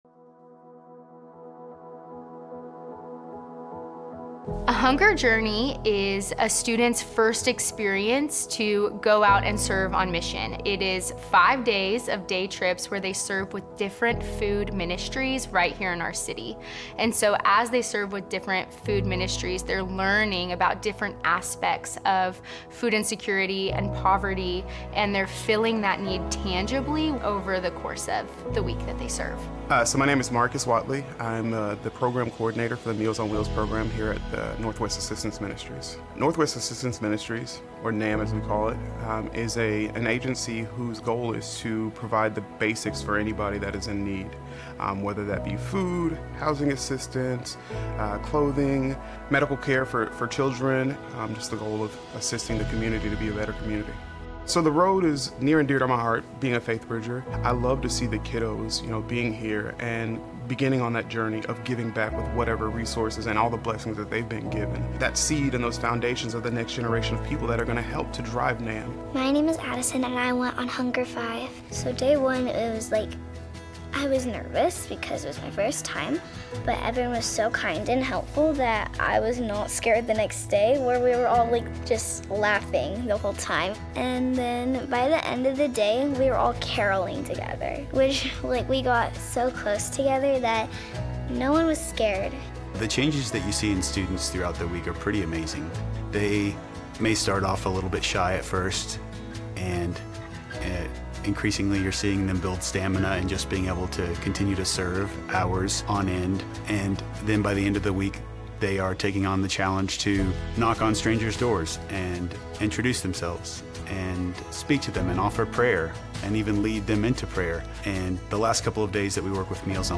Faithbridge Sermons If the Fire Starts to Fade Aug 03 2025 | 00:36:44 1x 00:00 / 00:36:44 Subscribe Share Apple Podcasts Spotify Overcast RSS Feed Share Link Embed